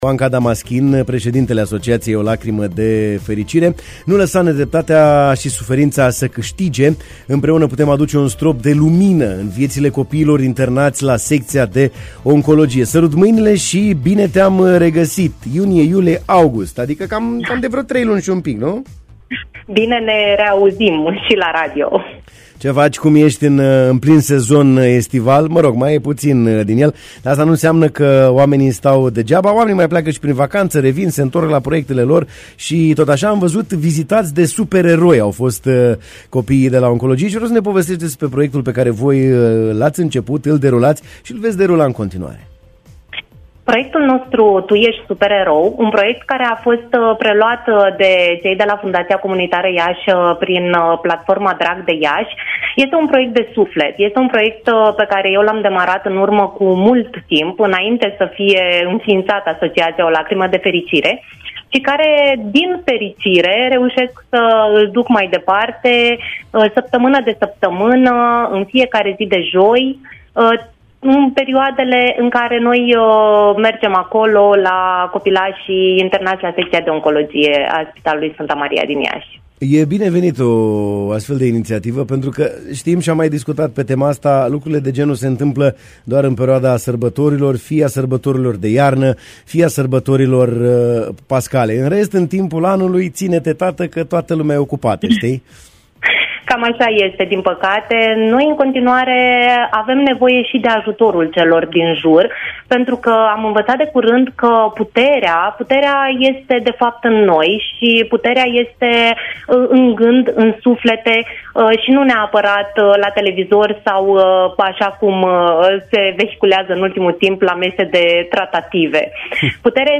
în matinalul de la Radio Iași: